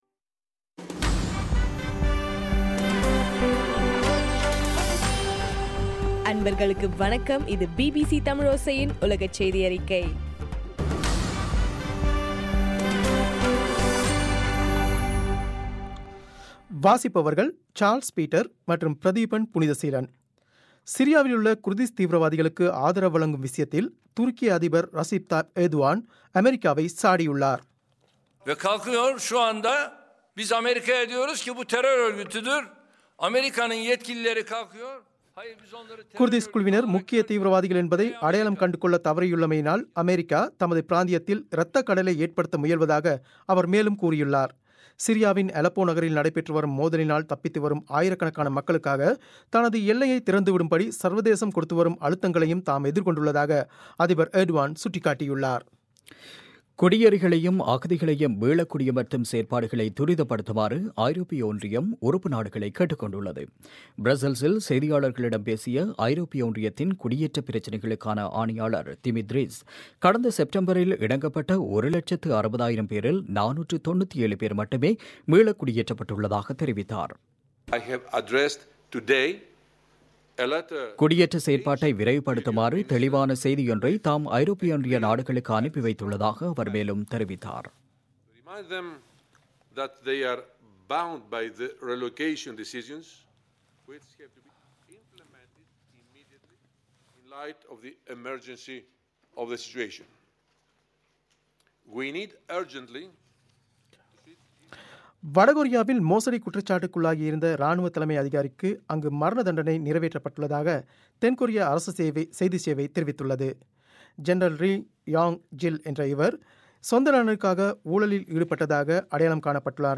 இன்றைய (பிப் 10) பிபிசி தமிழோசை செய்தியறிக்கை